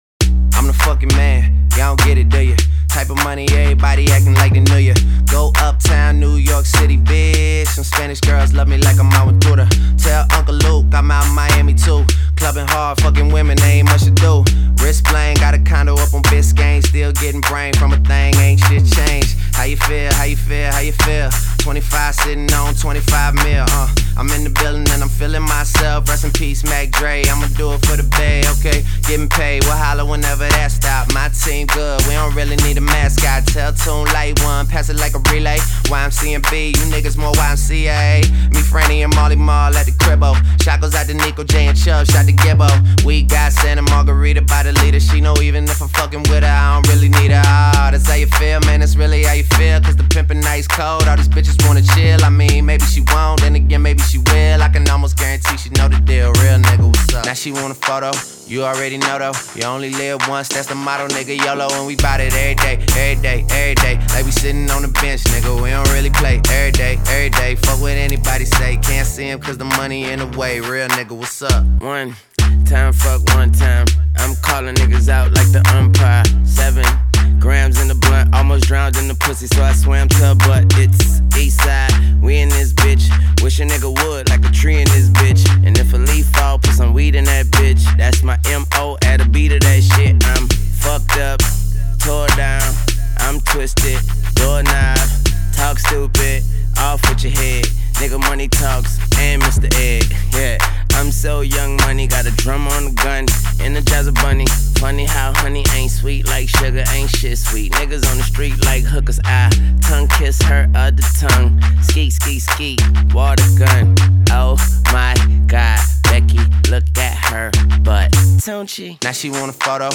minimalist beat